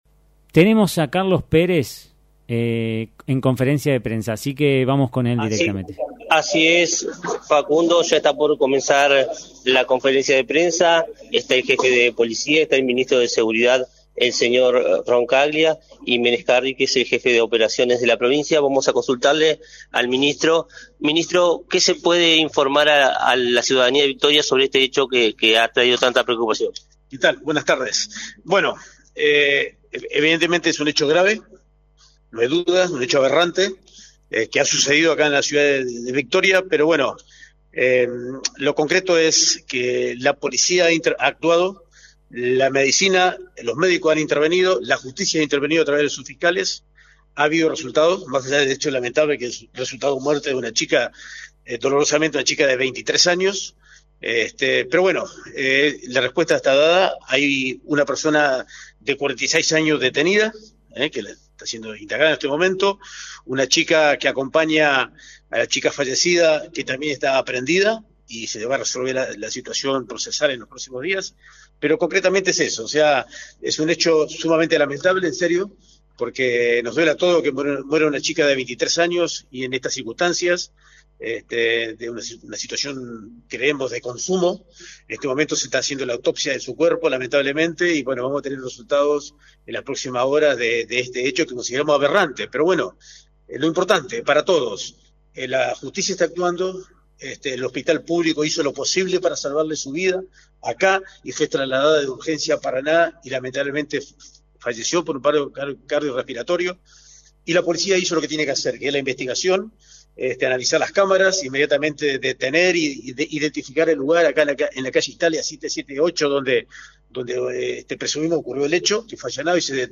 conferencia-de-prensa.mp3